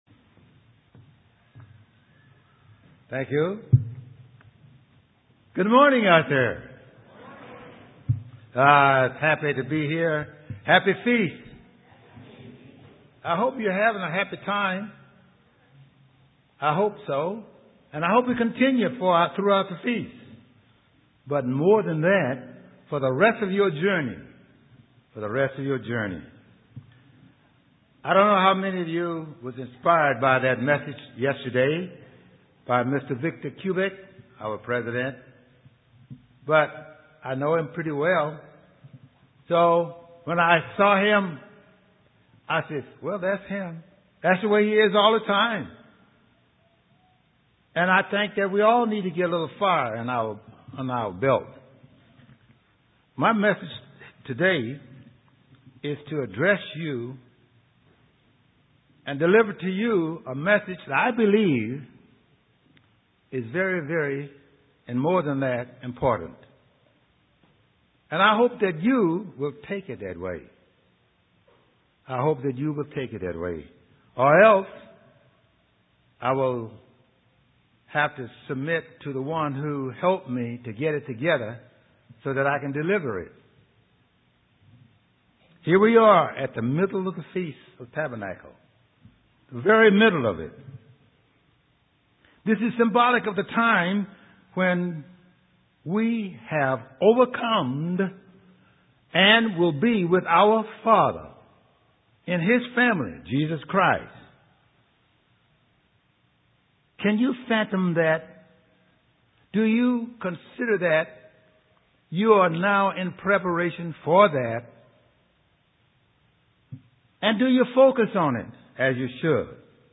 Day three FOT New Braunfels.
UCG Sermon